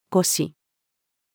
腰-posture-female.mp3